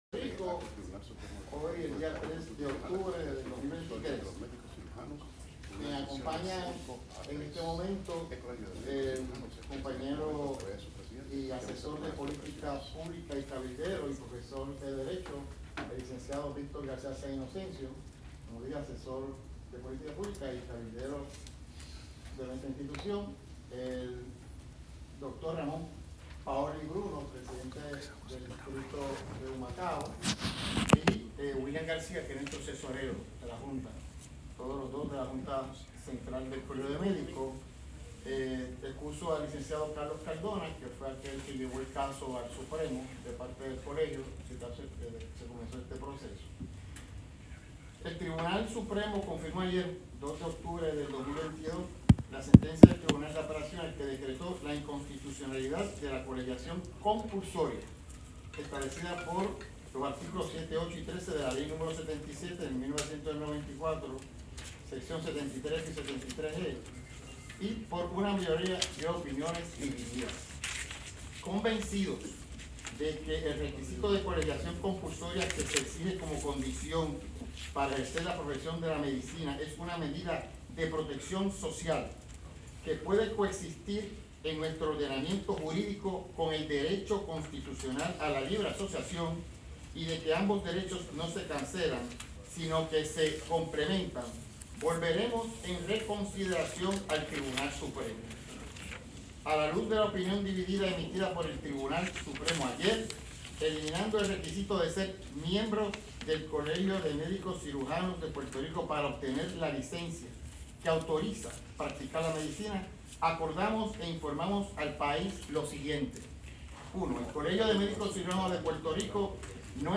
en conferencia de prensa.